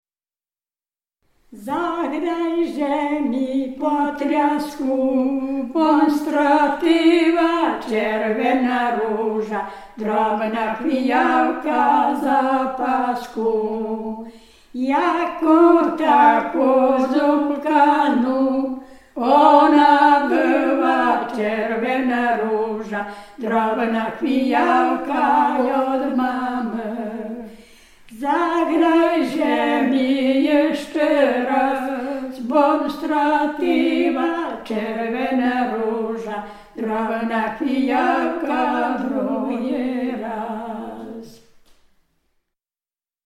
Dolny Śląsk, powat legnicki, gmina Kunice, wieś Piotrówek
Weselny taniec
Łemkowie